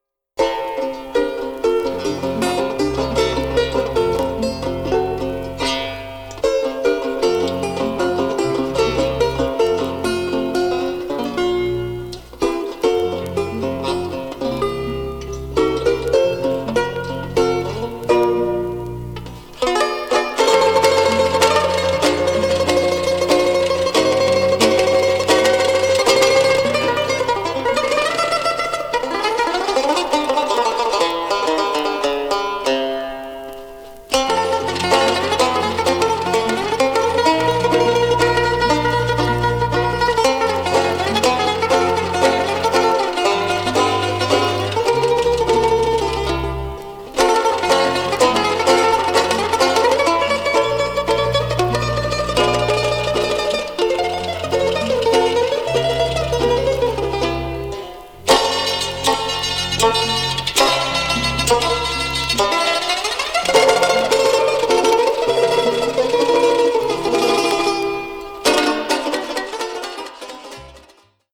This song is in 3/4 waltz time.